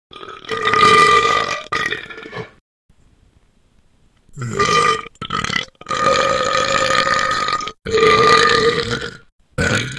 Громкая отрыжка:
burping4.wav